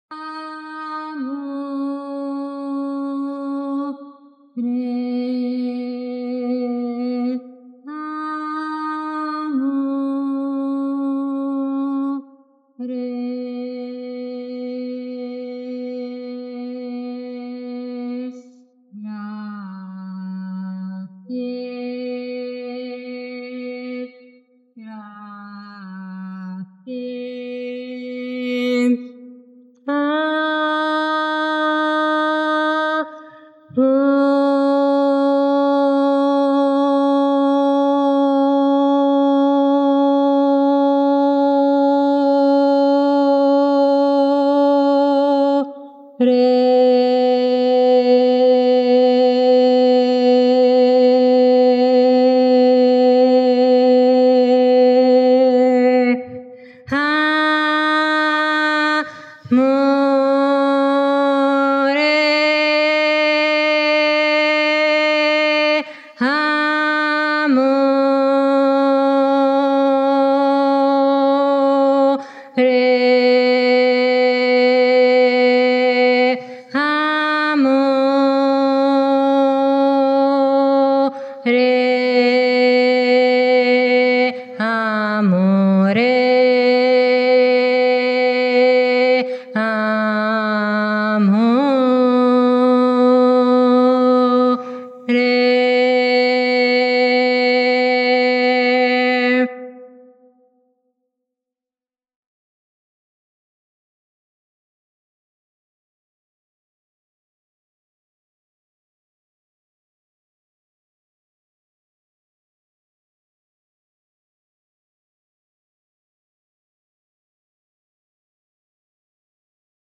Messaggio Canalizzato dei Grandi Maestri di Luce che è arrivato durante la presentazione a Lesmo 2025 del libro: Il Grande Gioco dell'Energia - Il Riconoscimento